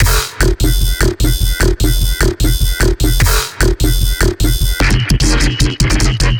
DUBSTEP & HYBRID TRAP
150BPM
BASS LOOPS 5 F
Antidote-Oblivion-150BPM-Bass-Loops-05-F-Bass.wav